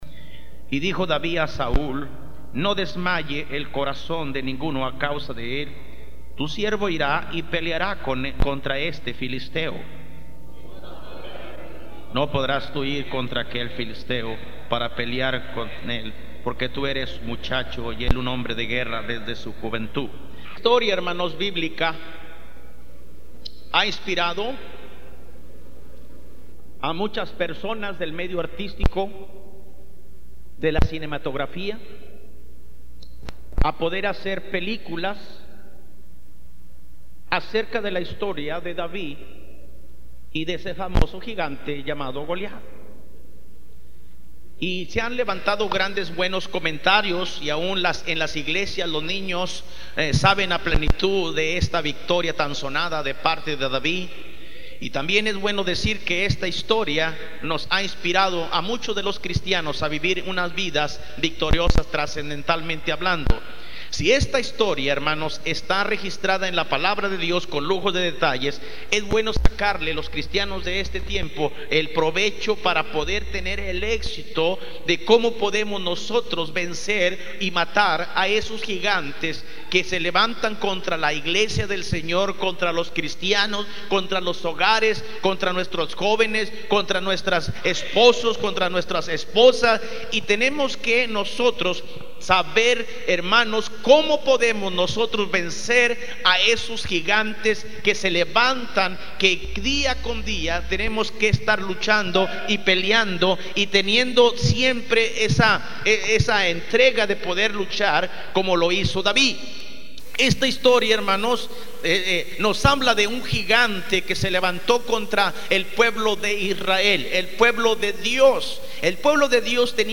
PREDICACIONES MP3 – IGLESIA BAUTISTA MONTE DE SION